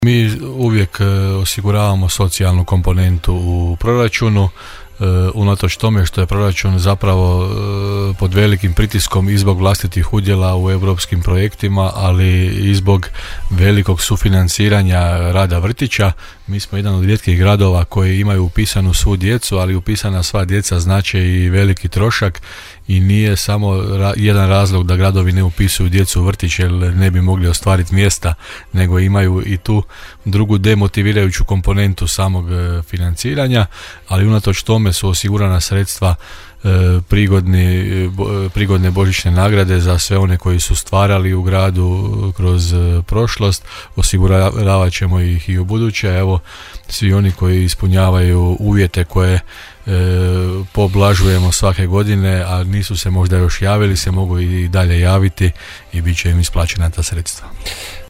-rekao je gradonačelnik Hrvoje Janči u emisiji Gradske teme.